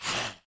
foot_act_1.wav